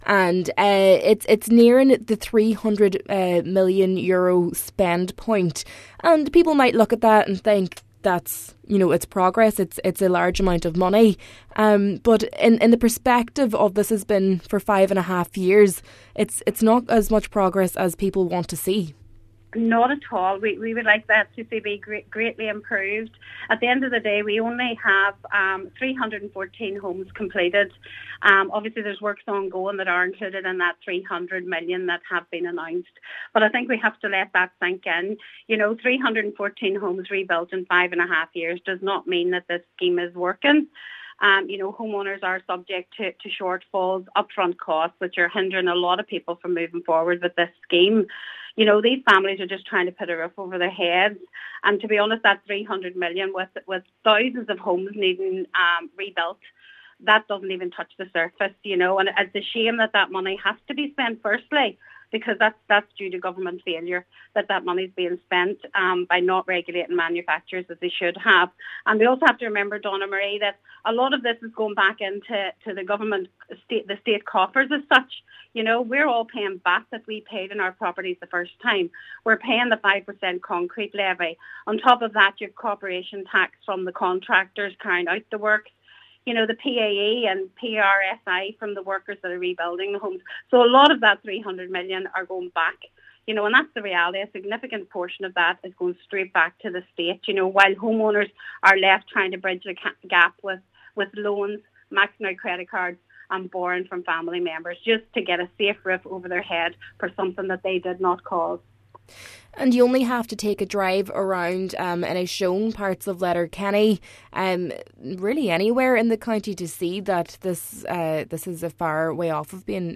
Cllr Beard says upfront costs are a major barrier preventing homeowners from accessing supports, and claims a portion of the funding is ultimately returning to the State through taxes: